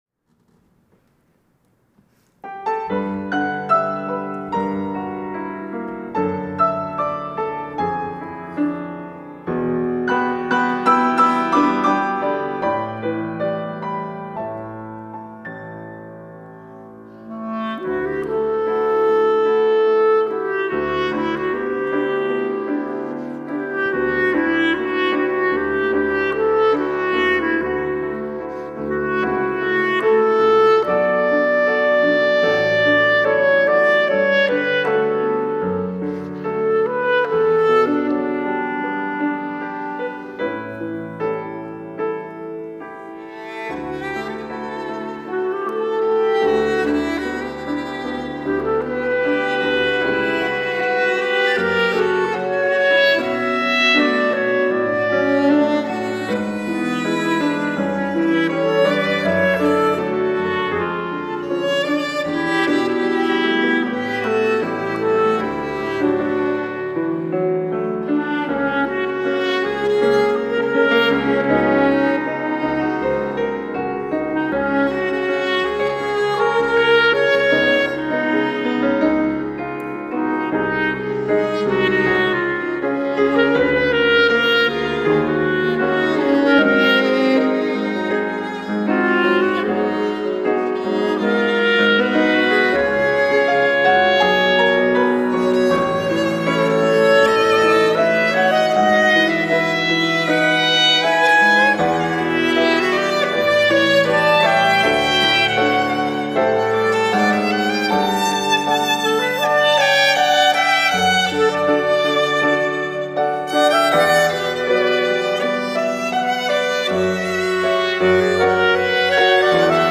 특송과 특주 - 시편 23편